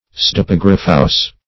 Search Result for " pseudepigraphous" : The Collaborative International Dictionary of English v.0.48: Pseudepigraphous \Pseu`de*pig"ra*phous\, a. [Gr.